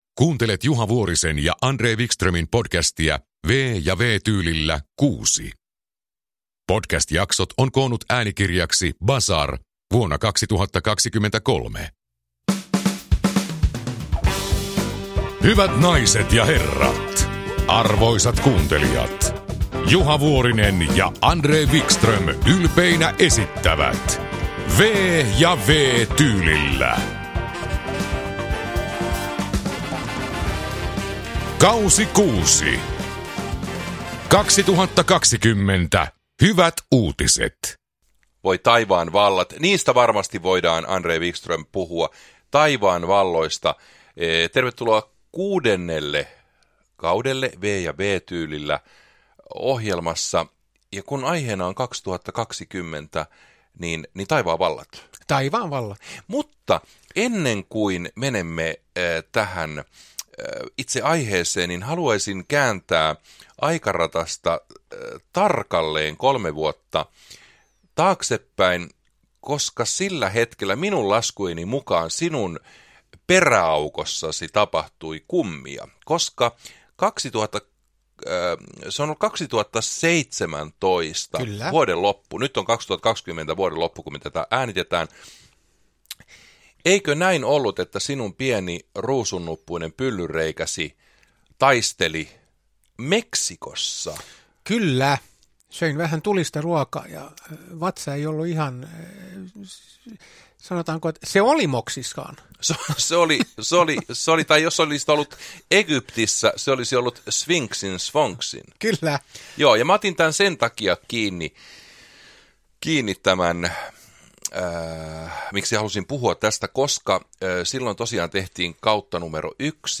V- ja W-tyylillä K6 – Ljudbok
Uppläsare: Juha Vuorinen, André Wickström